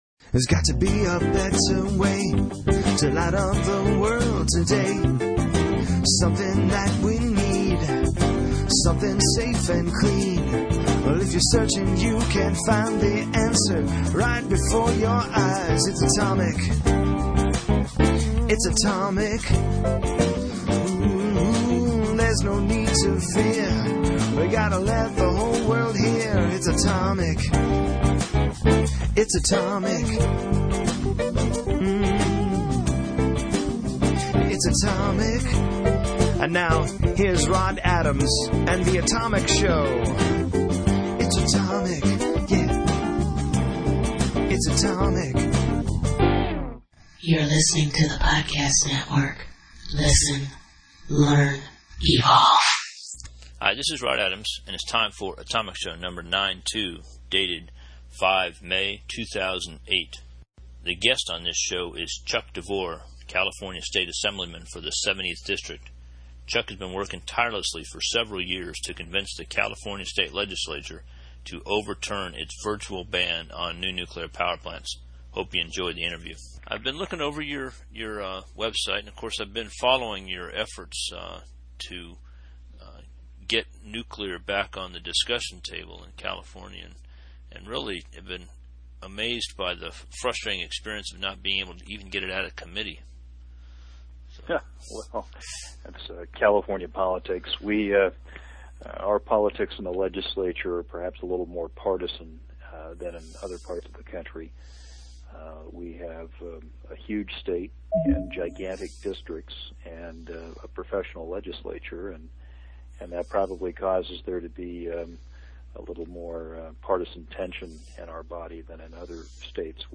He is an eloquent speaker with a good grasp of the technical details of why nuclear power plants should be built in California to serve the current and growing needs of the state’s large population base.